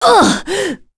Pavel-Vox_Damage_02.wav